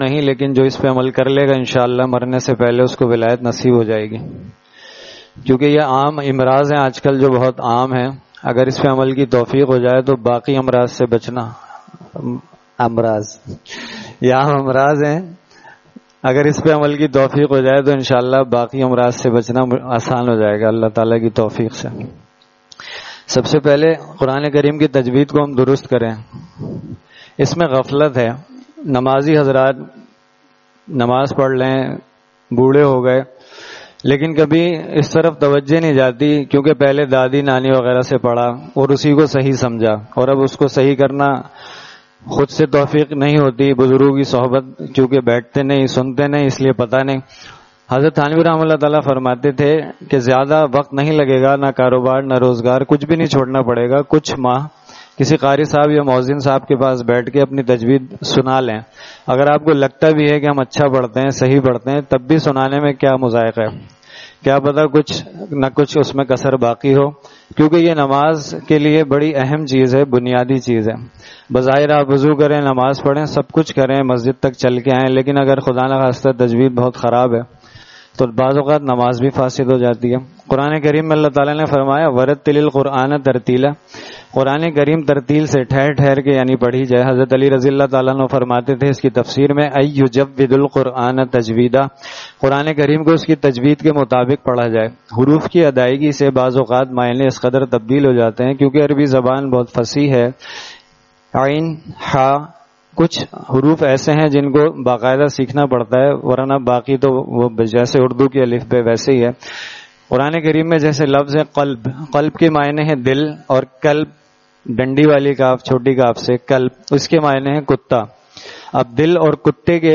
Saturday Markazi Bayan at Jama Masjid Gulzar e Muhammadi, Khanqah Gulzar e Akhter, Sec 4D, Surjani Town